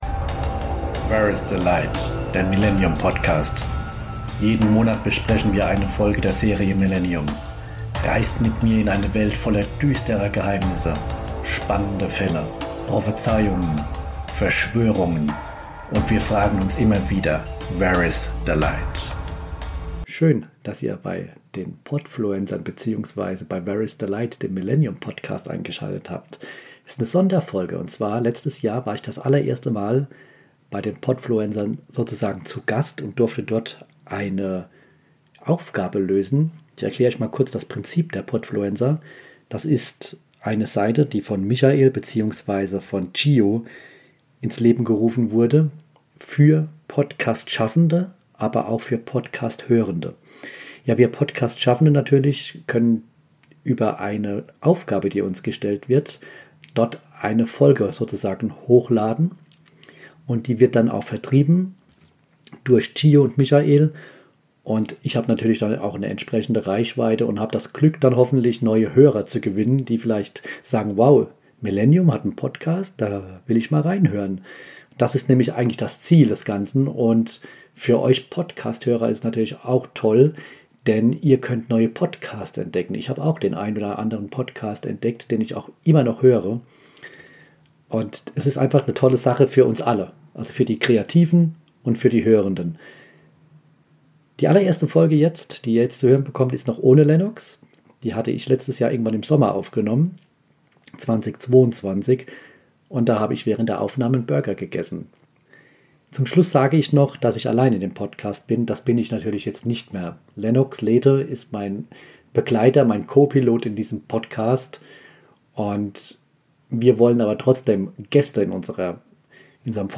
Ich sollte während der Aufnahme einen Burger essen.
MillenniuM muss nicht immer düster sein, es darf auch gelacht werden und Blödsinn veranstaltet werden.